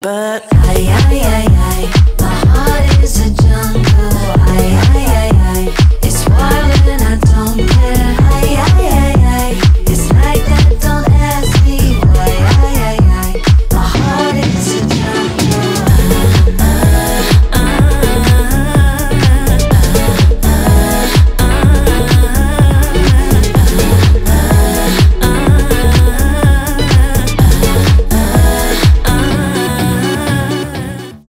uk garage , rnb